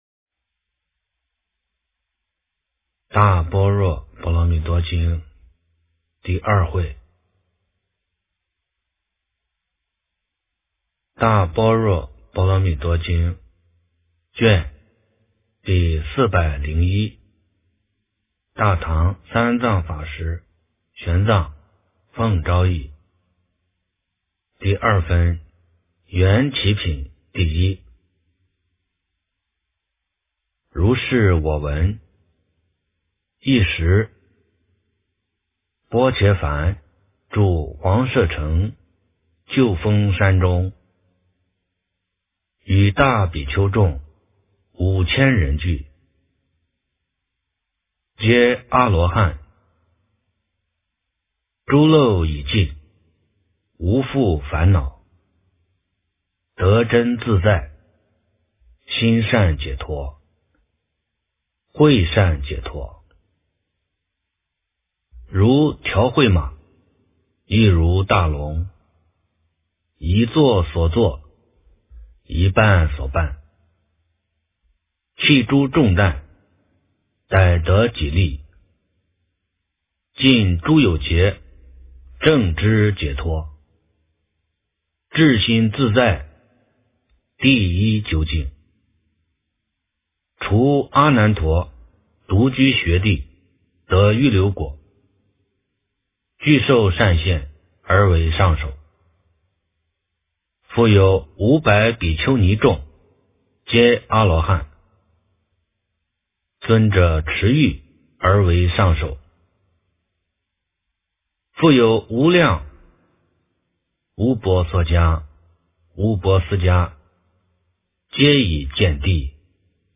大般若波罗蜜多经401卷 - 诵经 - 云佛论坛